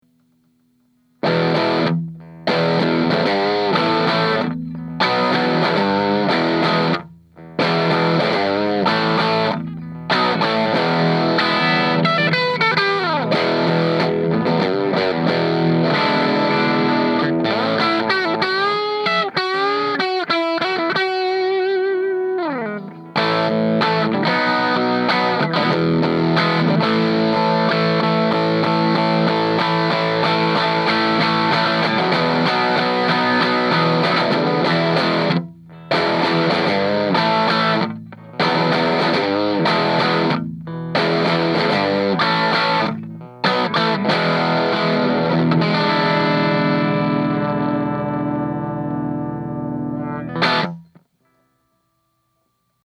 • Bridge-dirty
It can create some searing lead tones, but with the volume backed off, will provide lots of snap.